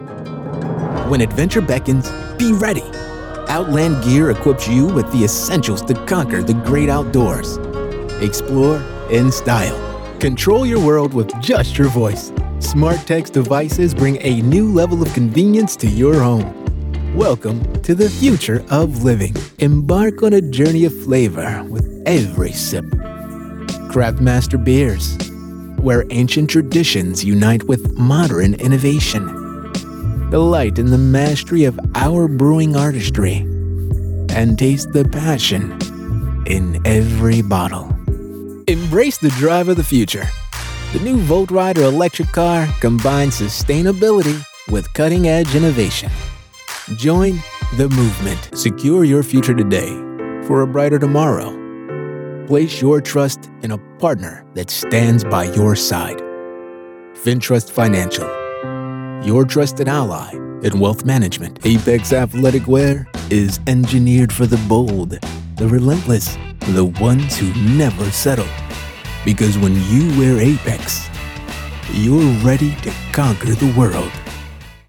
Commercial Demo
My tone flexes from confident and cool to clear and corporate, depending on your project. My reads are warm, grounded, and authentic.
Warm.
Based in North Las Vegas, I work from a broadcast-ready home studio.